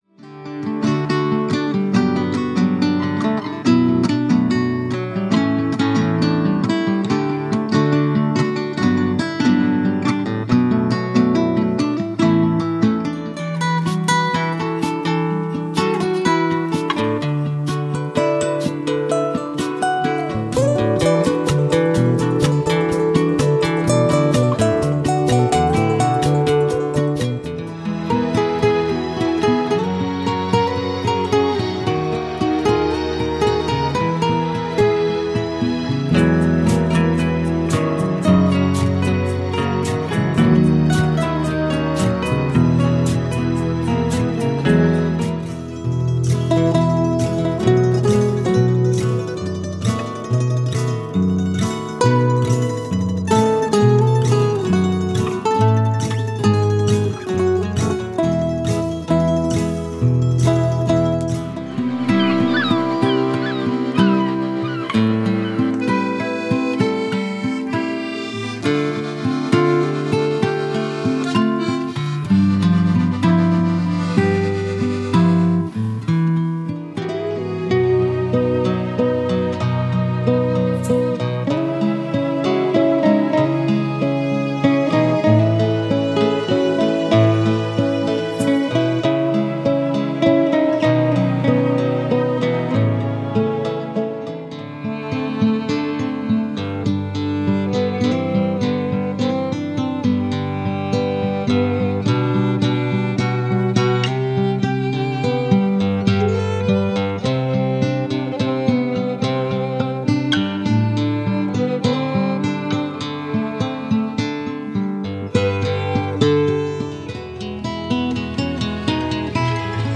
• Album Preview Audio Sample
truly relaxing, joyful, and a spiritual listening experience